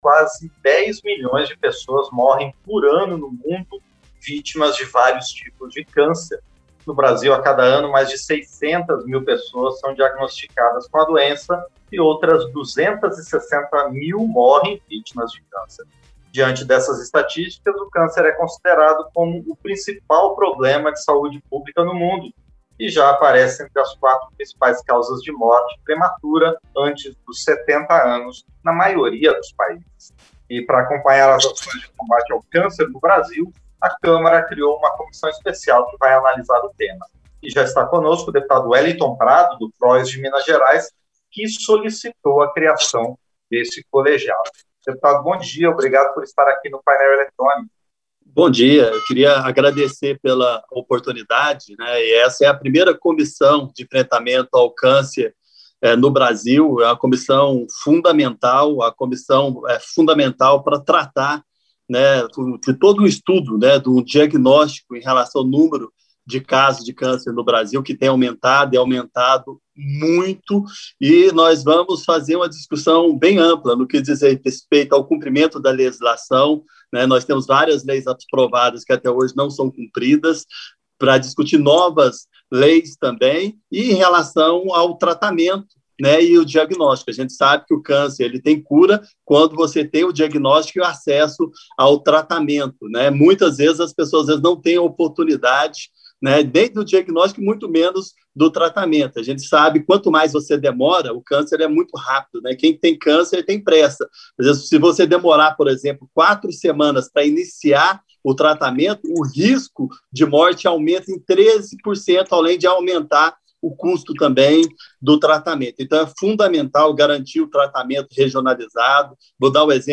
Entrevista - Dep. Weliton Prado (PROS-MG)